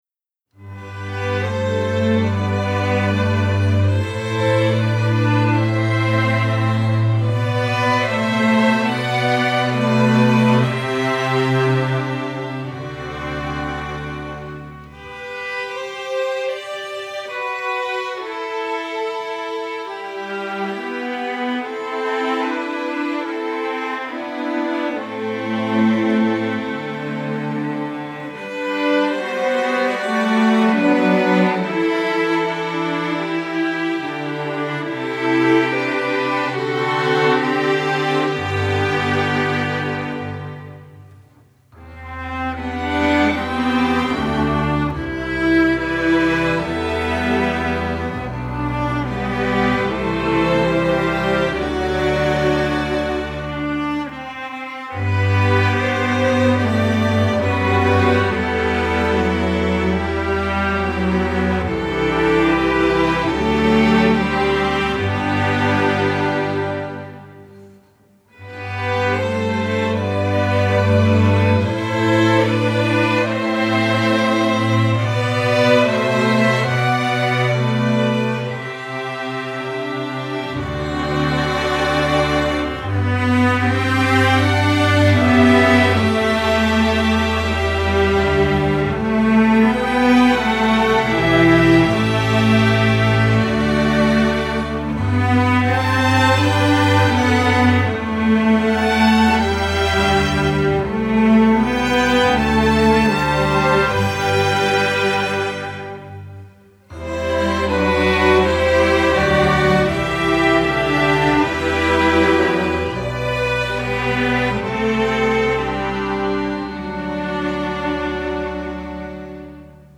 Instrumentation: string orchestra
masterwork arrangement, sacred
Piano accompaniment part: